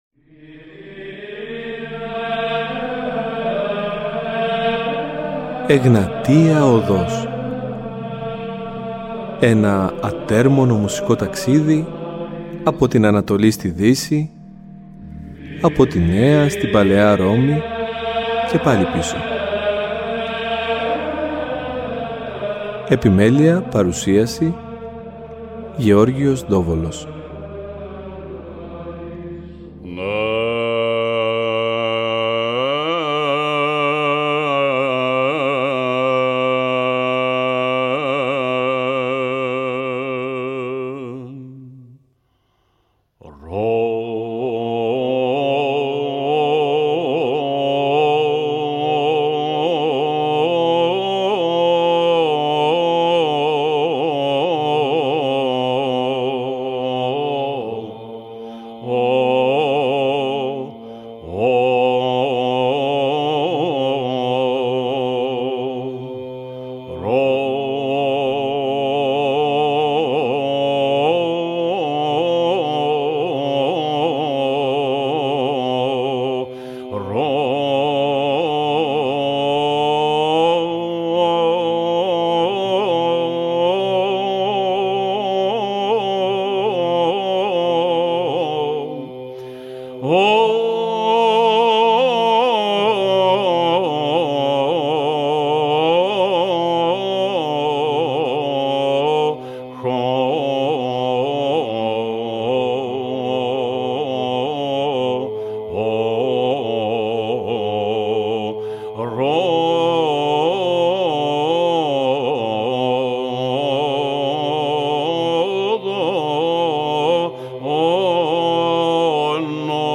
Πρόκειται στην ουσία για συνθετικά σύνολα που αναπτύσσονται πλατύτερα σε σχετικά νεώτερα χρόνια (16ος αιώνας και εξής) Στις εκπομπές αυτού του Σαββατοκύριακου ακούμε δύο τέτοιες έξοχες συνθέσεις οι οποίες αναπτύσσουν ολόκληρη την οκτωηχία στο εσωτερικό τους και μάλιστα με τρόπο διαδοχικό και παράλληλα κυκλωτικό .
Ορθοδοξη Εκκλησιαστικη Μουσικη